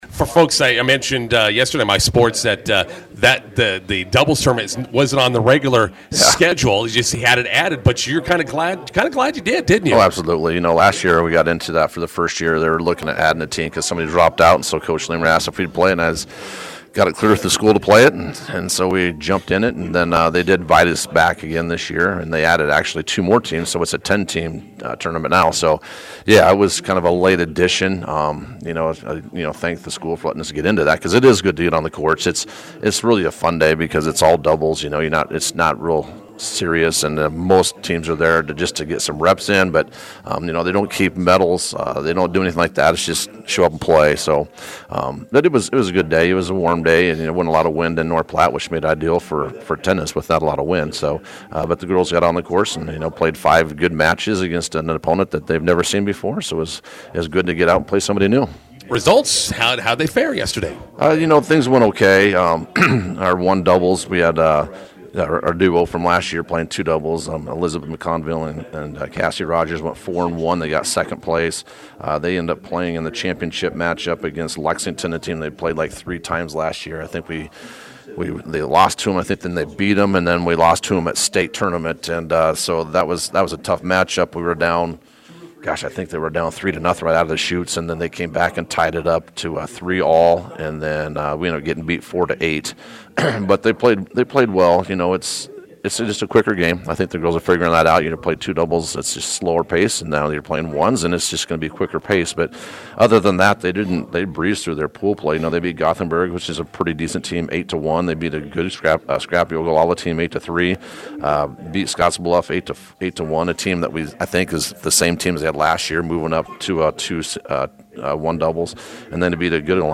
INTERVIEW: Bison girls tennis finish second at NP Doubles Tournament.